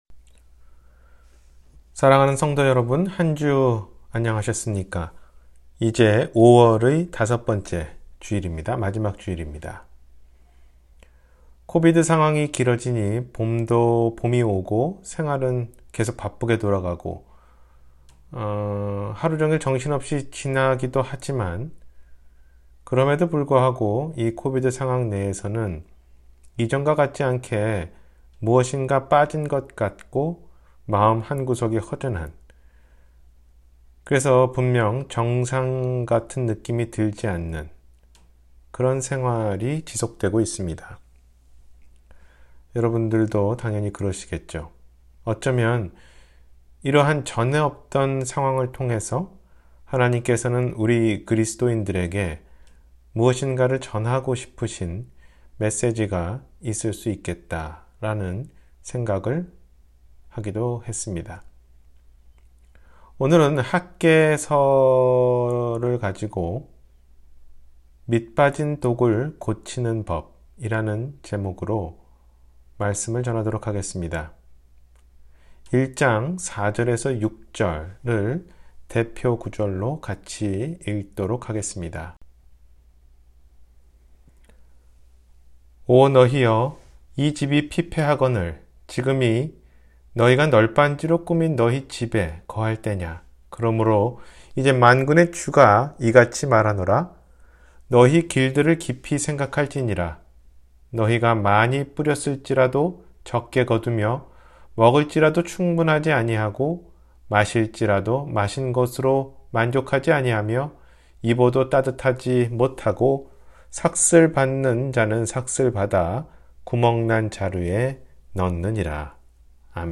밑빠진 독을 고치는 법 – 주일설교